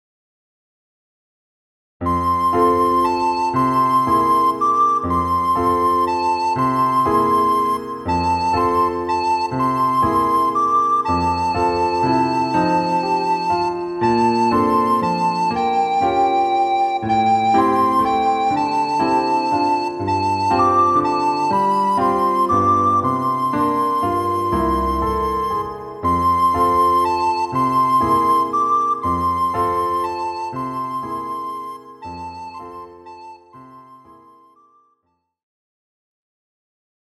A breezy, easy-going Waltz.
Key: F major (but uses only 5 notes - G, A, B, C, D)